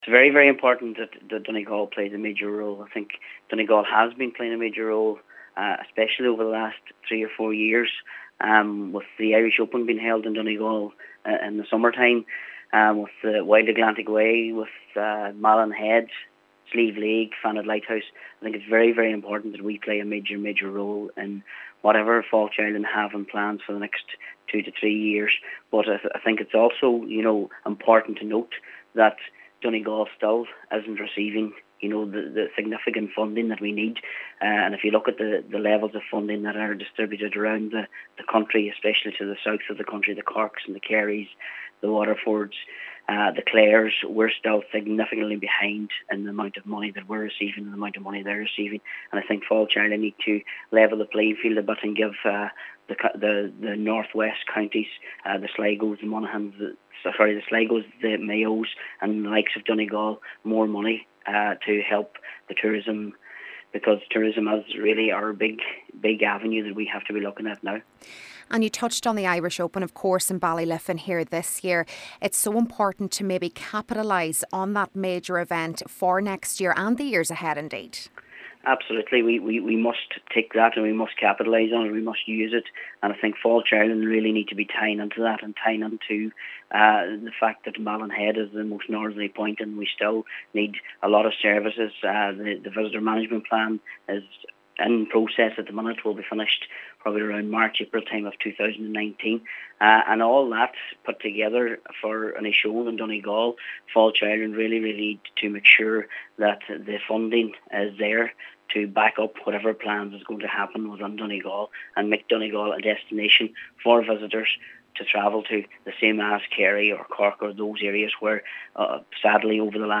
Cllr. Martin McDermott says the overall plan seems to have more of a focus on southern parts of the country, and more funding must be made available to the county to level out the playing field: